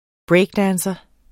Udtale [ ˈbɹεjgˌdæːnsʌ ]